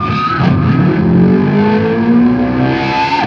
DIVEBOMB16-L.wav